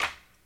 TC Clap Perc 05.wav